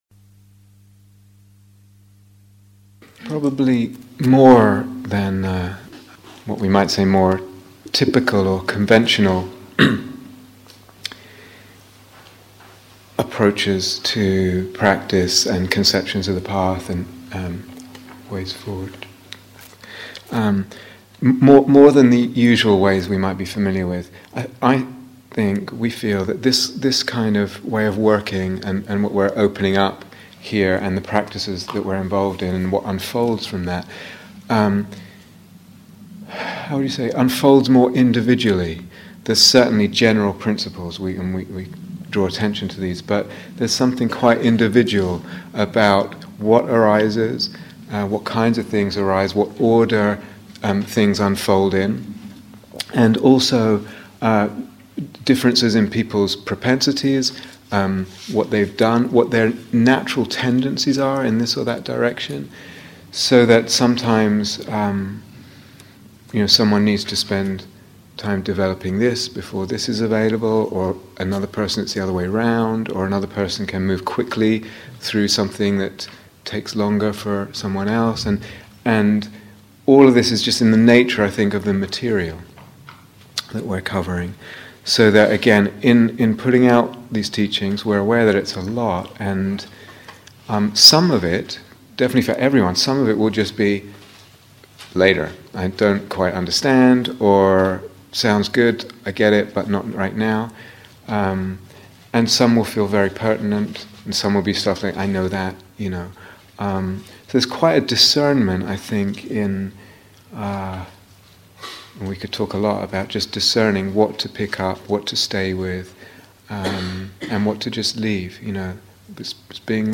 The Movement of Devotion (live and shortened version)